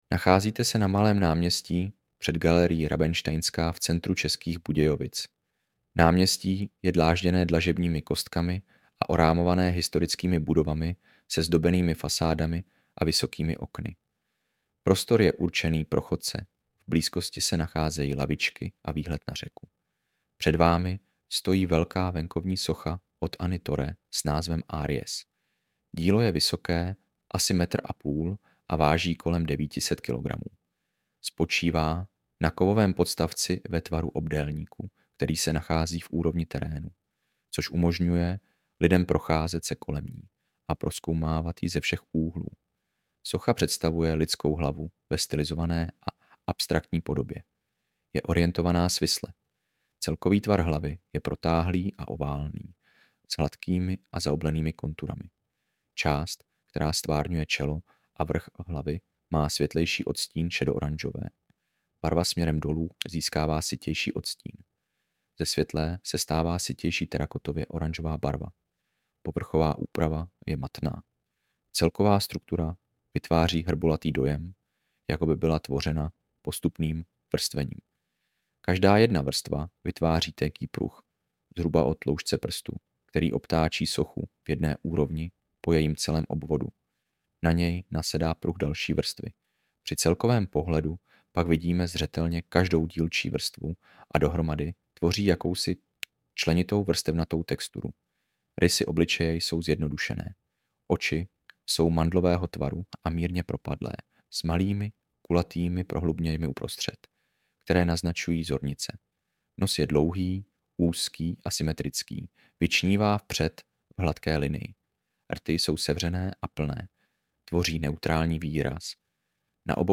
AUDIOPOPIS